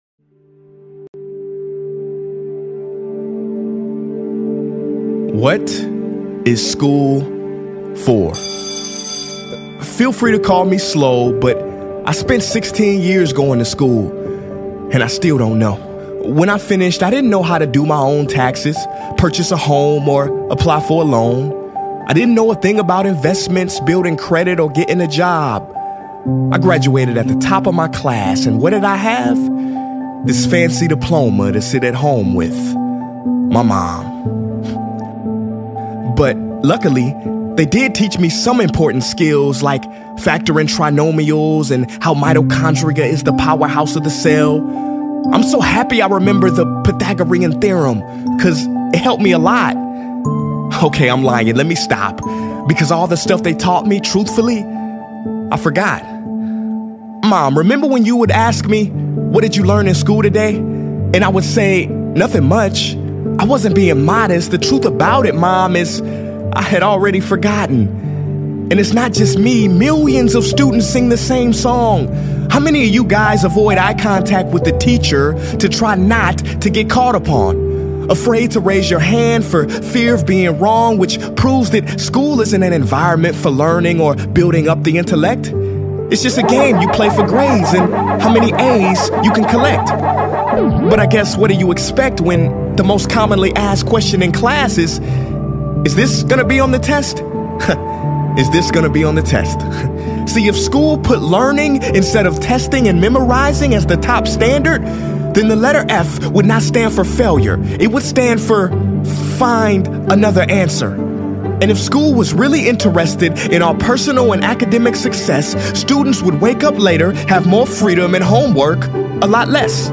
Credit: The Speaker Prince Ea